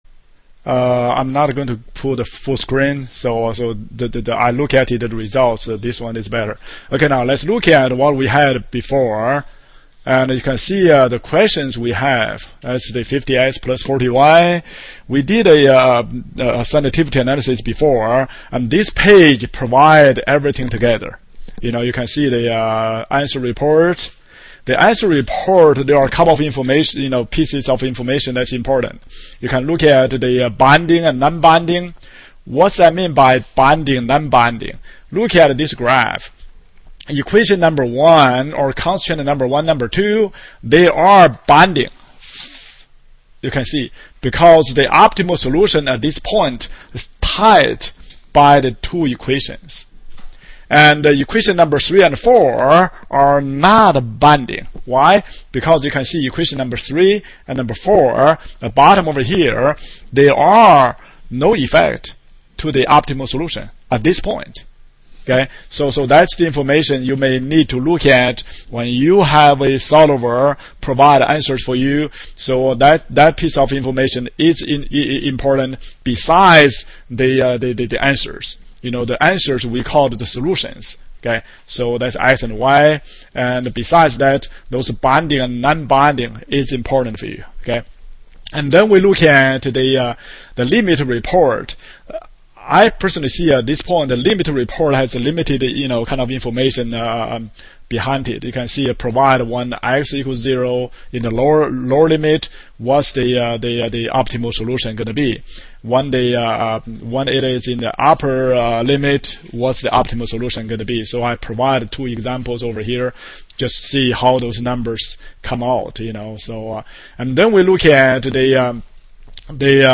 LPSALecture2.mp3